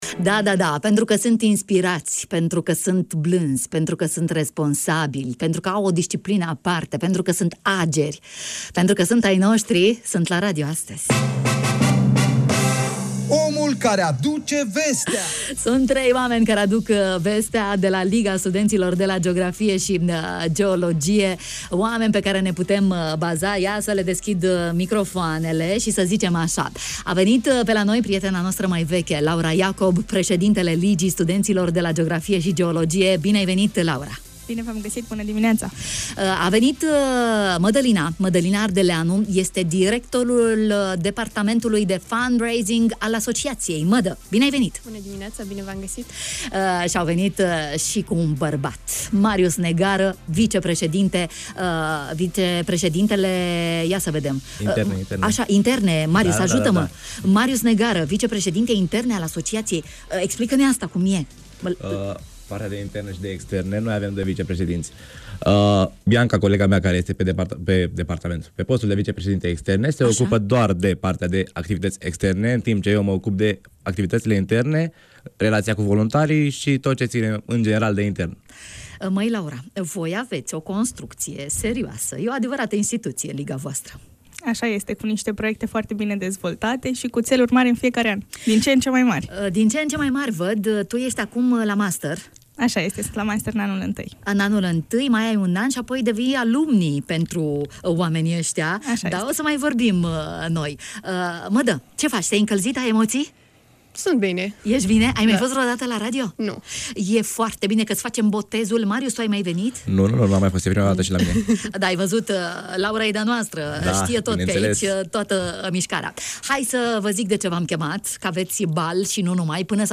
A venit momentul mult așteptat de toată lumea, și anume cea de a XIX-a ediția a Balului Bobocilor al Facultății de Geografie și Geologie! Evenimentul va avea loc pe 21 noiembrie la Casa de Cultură a Studenților la ora 19:00 și îți propunem un spectacol de talente memorabil. Invitați în matinal